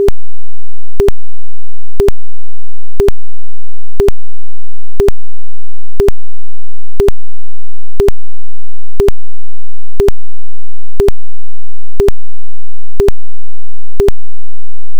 click here to hear an audio transposition of our P-band (lower frequency, foliage/ground penetrating) waveform).
chirp_Pband.ogg